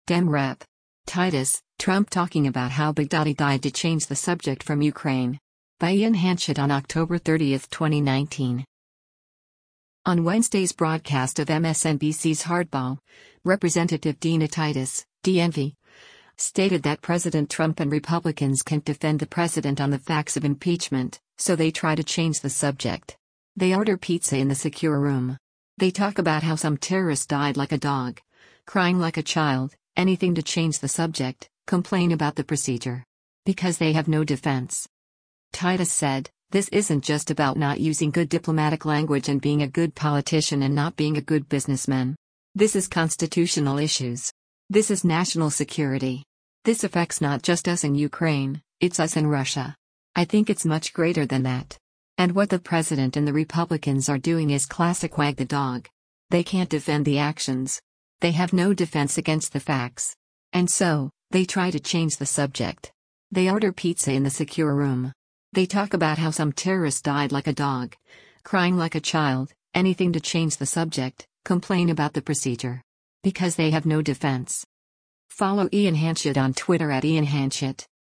On Wednesday’s broadcast of MSNBC’s “Hardball,” Representative Dina Titus (D-NV) stated that President Trump and Republicans can’t defend the president on the facts of impeachment, so “they try to change the subject. They order pizza in the secure room. They talk about how some terrorist died like a dog, crying like a child, anything to change the subject, complain about the procedure. Because they have no defense.”